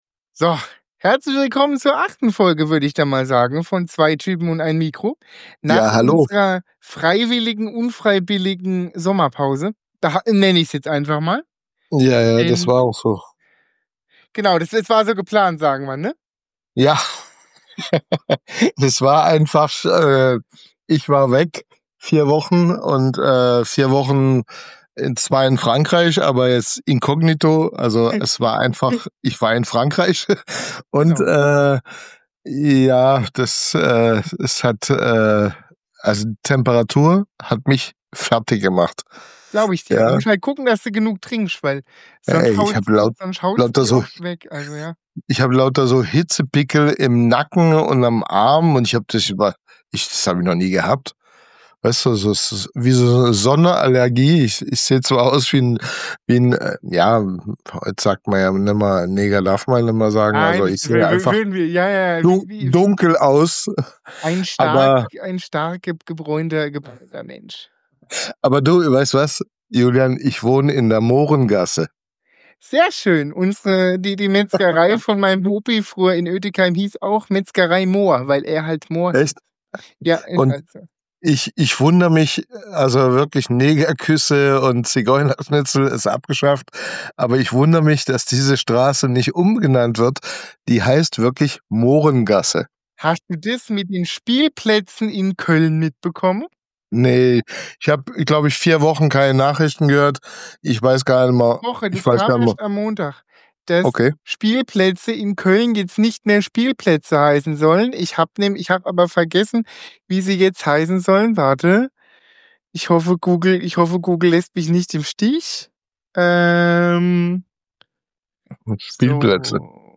Eine Stunde mit zwei Typen